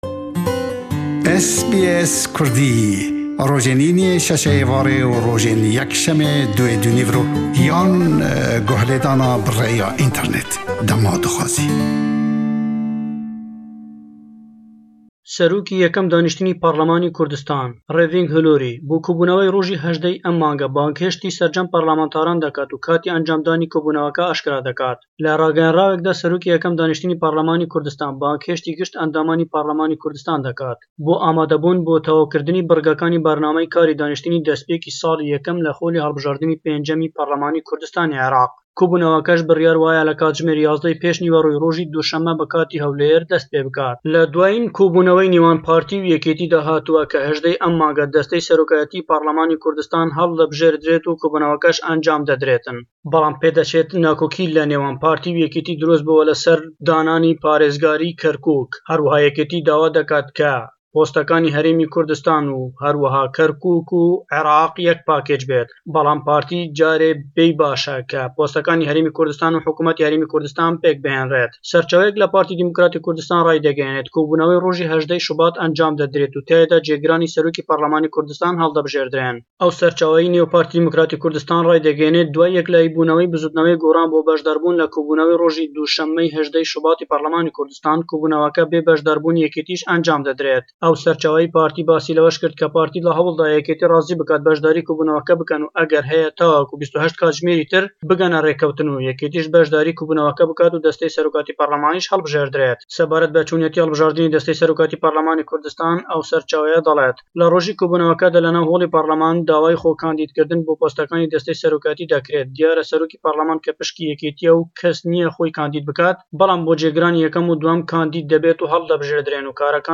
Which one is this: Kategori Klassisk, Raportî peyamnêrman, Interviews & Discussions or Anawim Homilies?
Raportî peyamnêrman